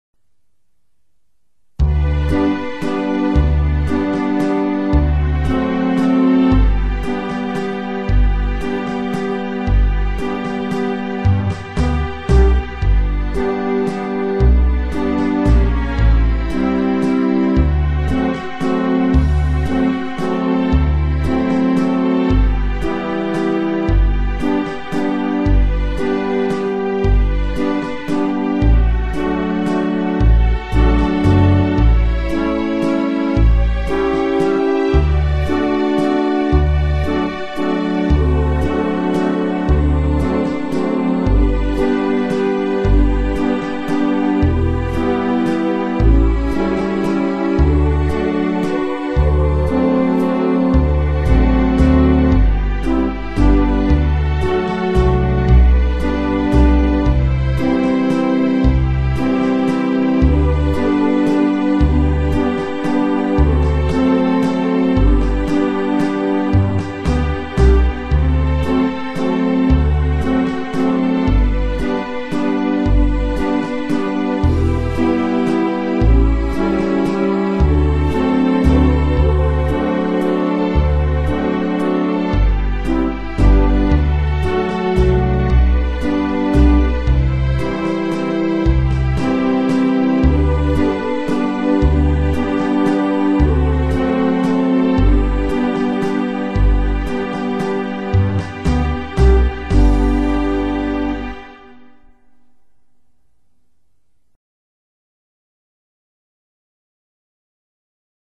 Solo Ringer
Hymn Tune